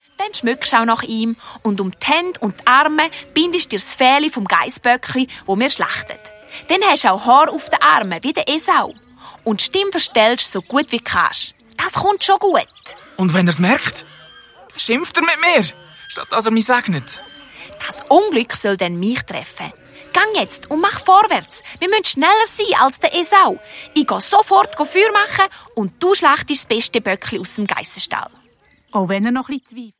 Zusätzlich enthalten: Mehrere Songs, das Hörspiel "De Striit ums Holz" der lustigen Bärenkinder der Adonia-KidsParty
Hörspiel - Album